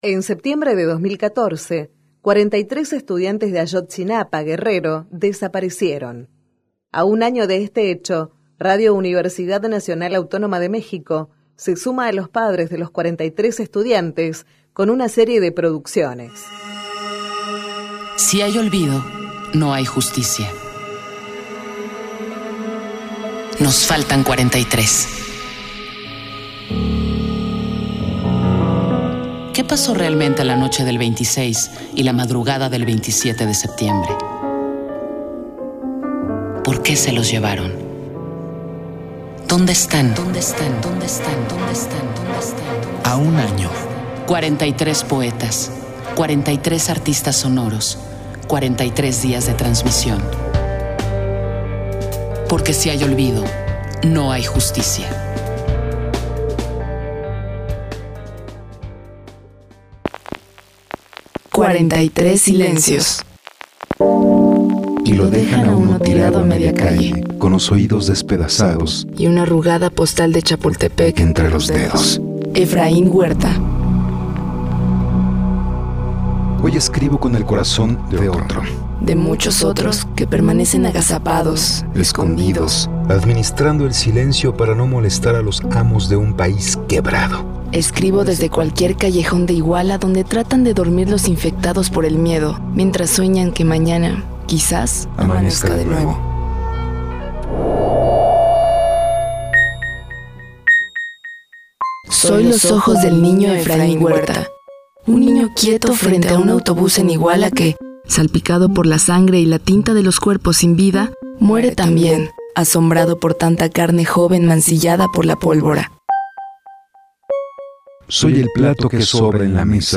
A un año de los sucesos, la producción de Radio UNAM reúne distintas personalidades del ámbito radiofónico que, entre poesía, música y ambientes sonoros, rescatan la memoria viva del sonado caso de los estudiantes desaparecidos de la Escuela Normal Rural de Ayotzinapa.